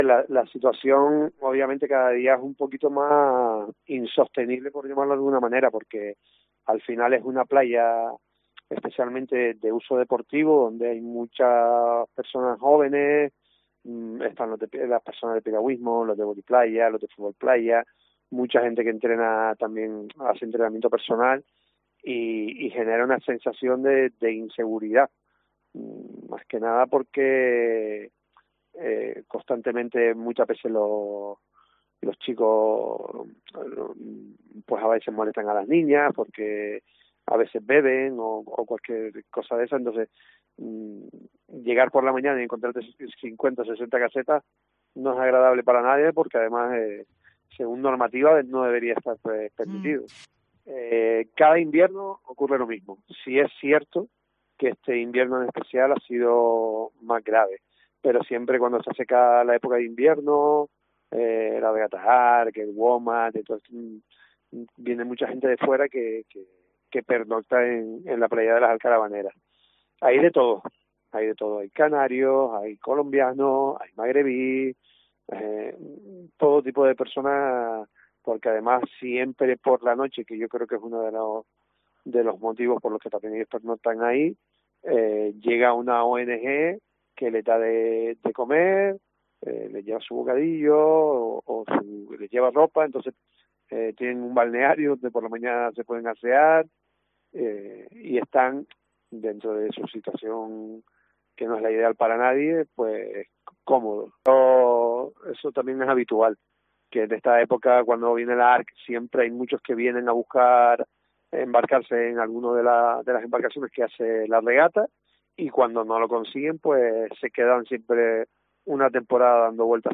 usuario asiduo de la playa de Las Alcaravaneras